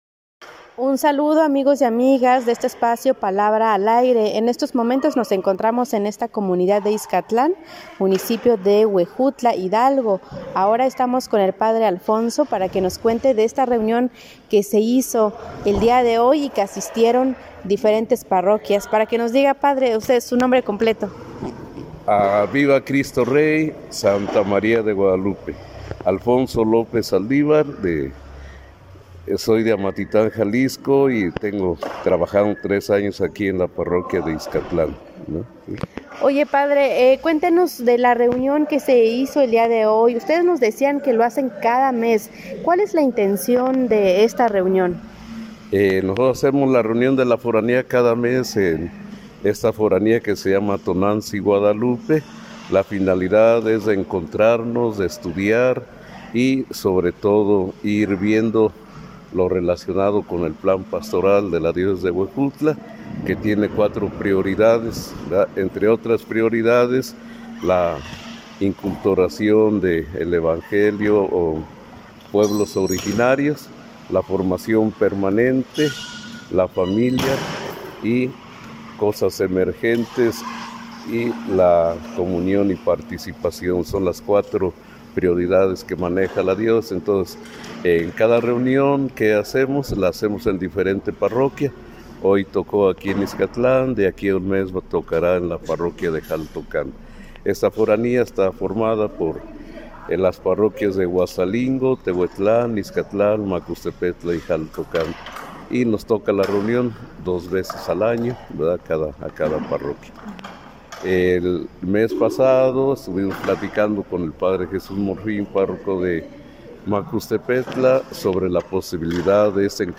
Este lunes 05 de Mayo en Ixcatlán, Huejutla, Hgo., se llevó acabo la reunión de la Forania de las 5 parroquias de la Huasteca.